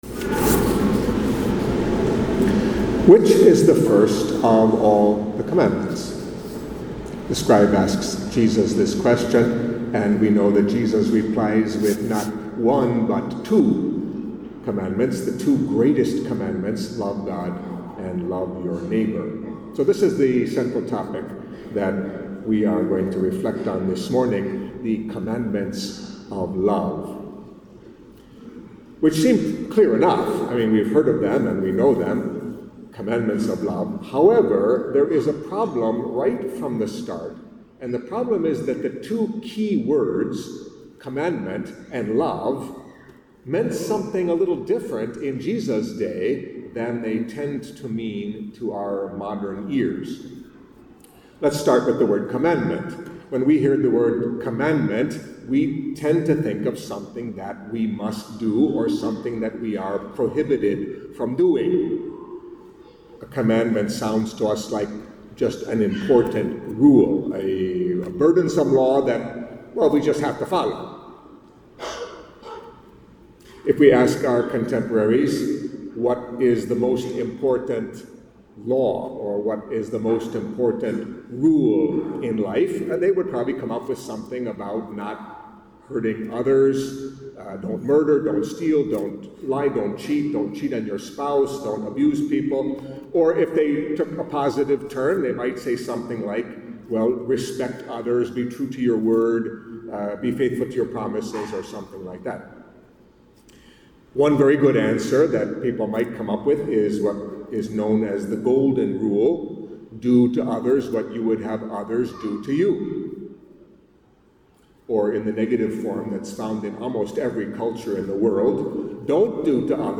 Catholic Mass homily for the Thirty-First Sunday in Ordinary Time